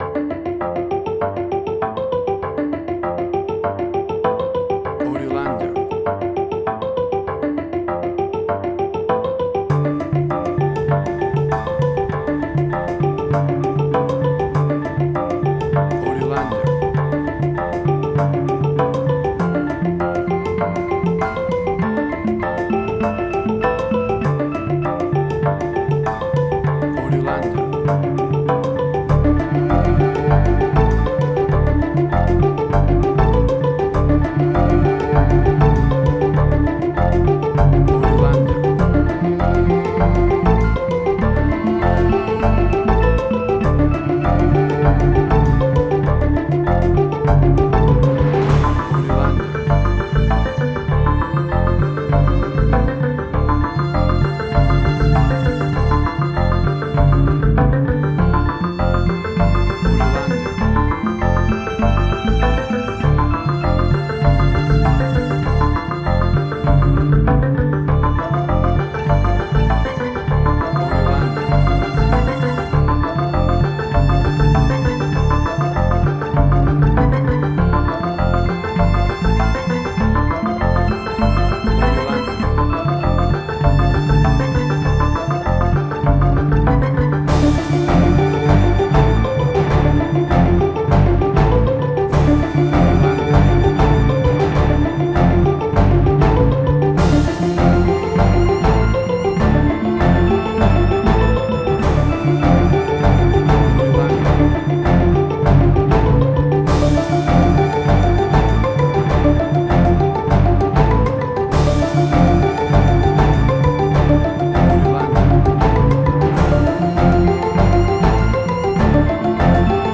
Suspense, Drama, Quirky, Emotional.
Tempo (BPM): 99